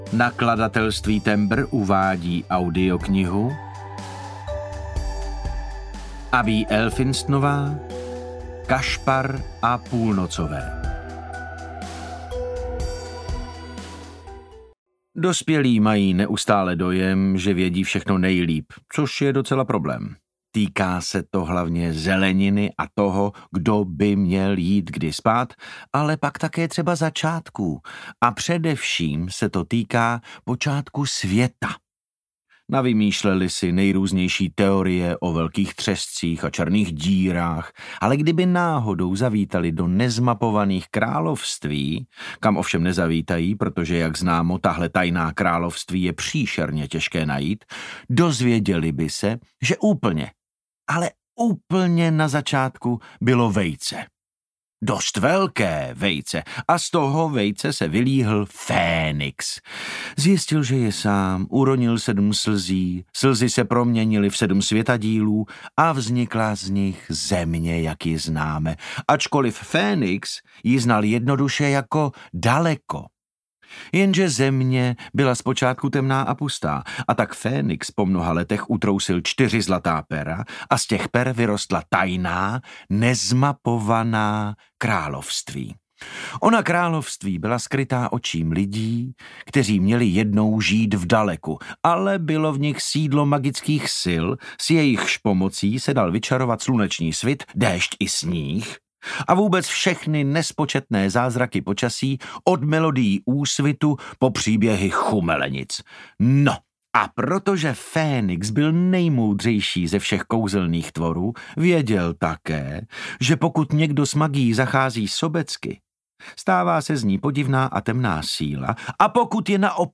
Kašpar a půlnocové audiokniha
Ukázka z knihy
• InterpretDavid Novotný